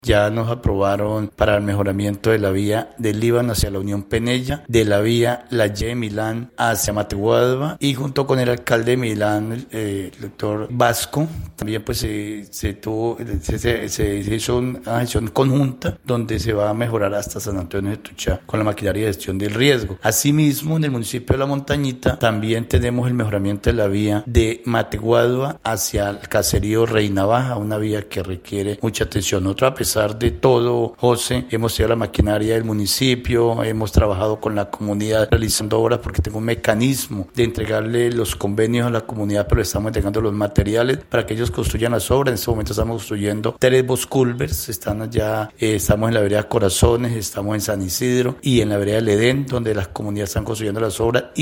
ALCALDE_FEDERICO_ALVIZ_VIAS_-_copia.mp3